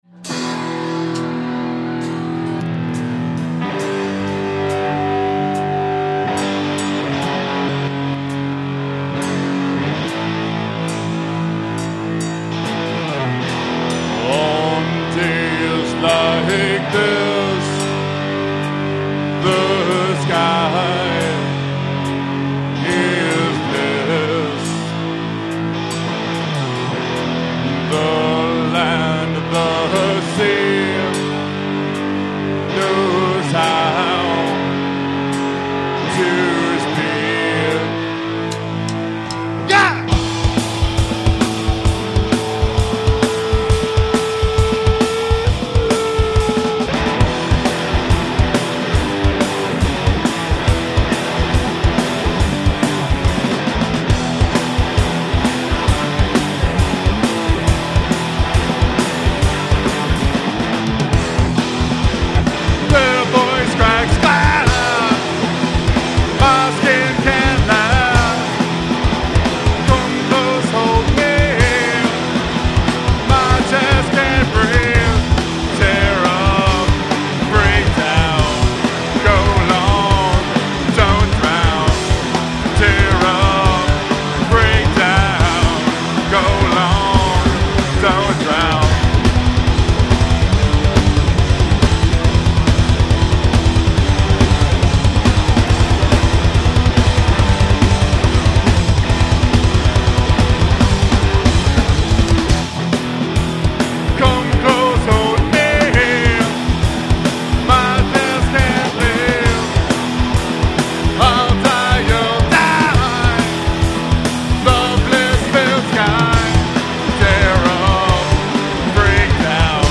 Live at The Red Sea